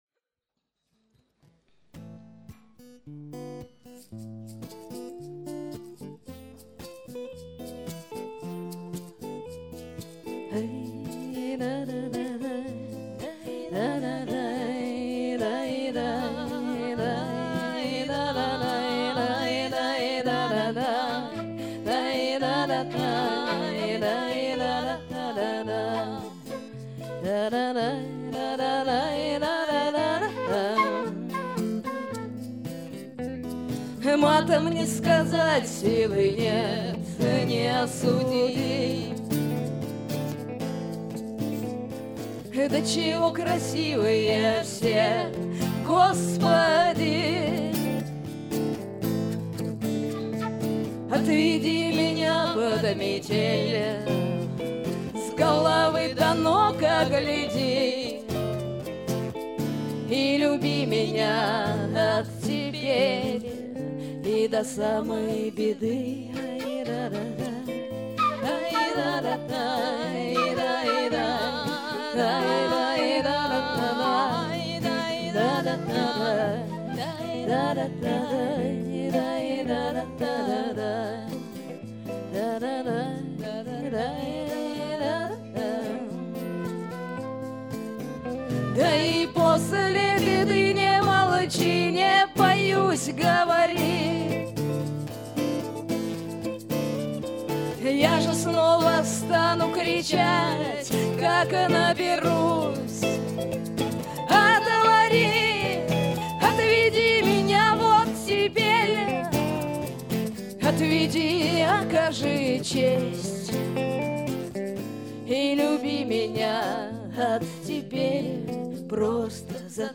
фольклорных интонациях произведения и составных рифмах